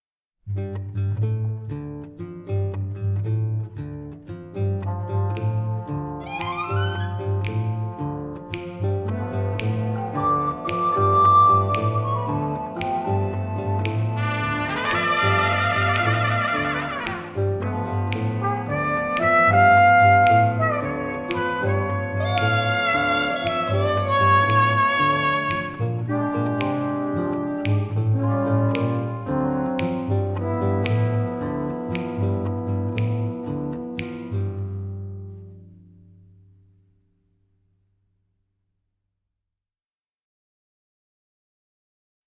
Musique de scène